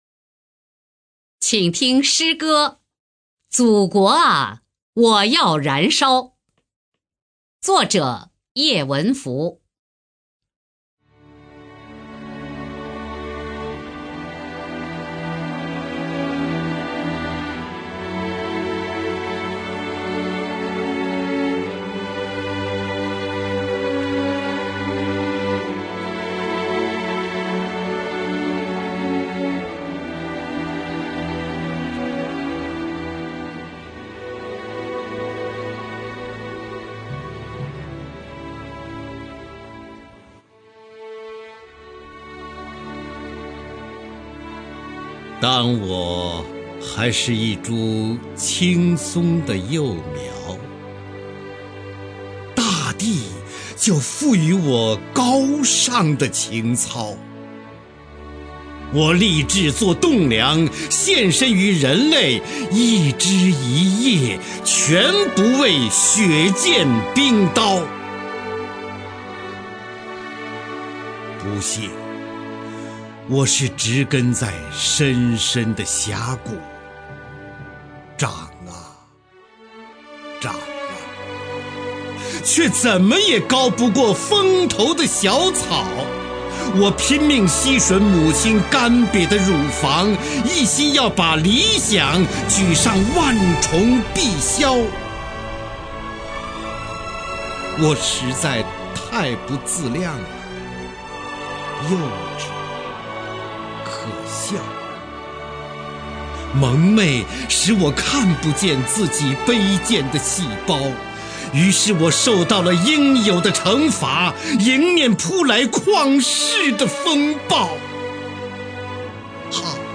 [8/9/2009]方明朗诵 叶文福的现代诗《祖国啊，我要燃烧》( 320K MP3)